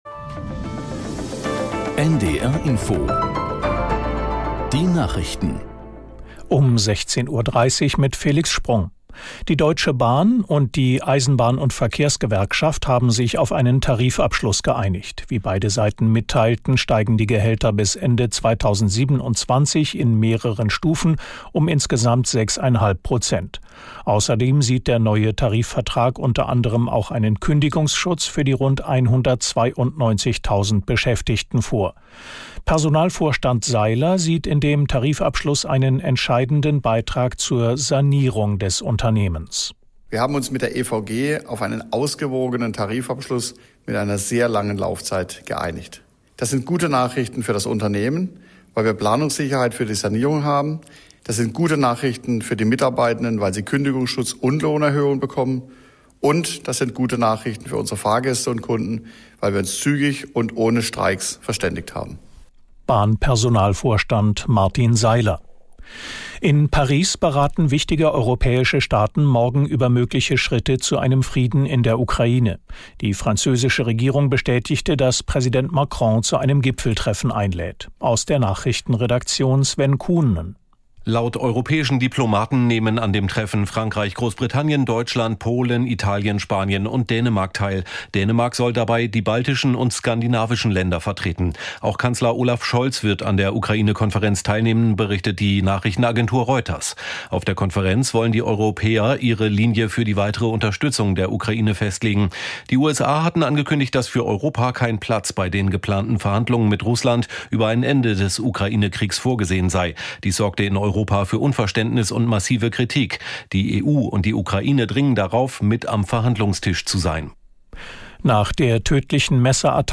Nachrichten - 16.02.2025